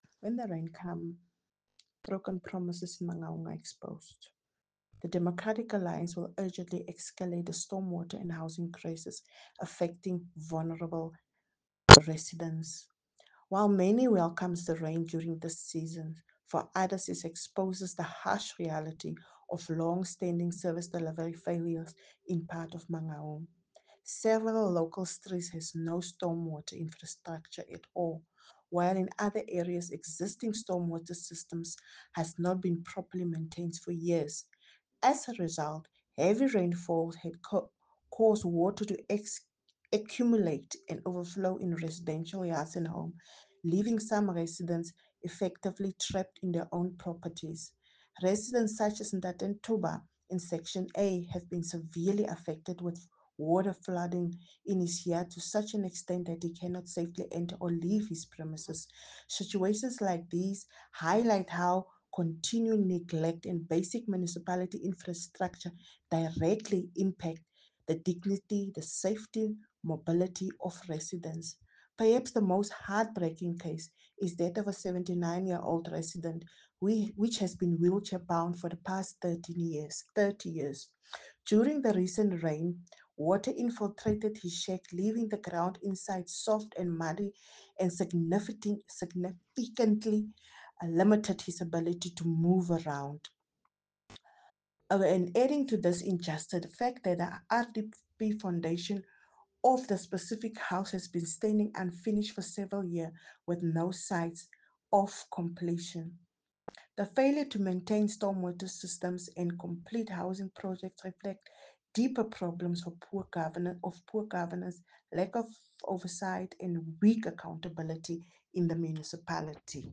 Afrikaans soundbites by Cllr Raynie Klaasen and